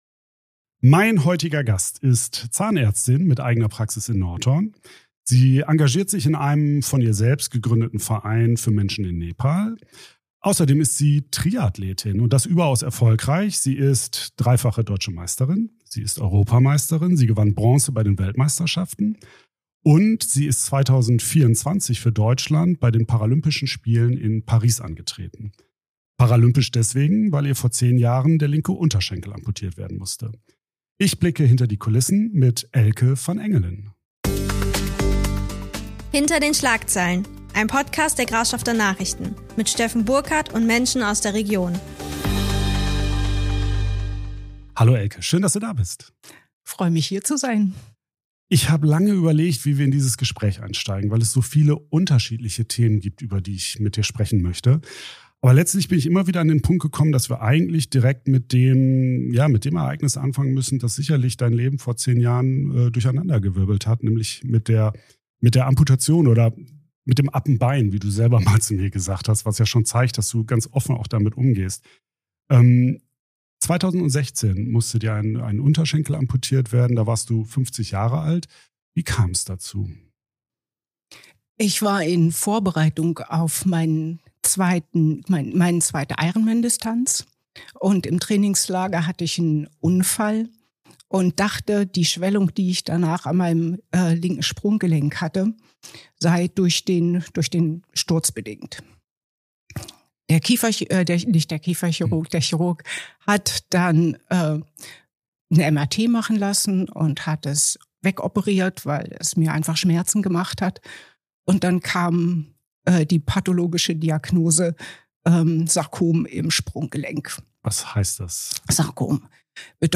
Ein Gespräch über Durchhaltewillen, Dankbarkeit, Rückschläge, gesellschaftliches Engagement – und die Haltung, sich nicht mit anderen zu vergleichen, sondern mit sich selbst.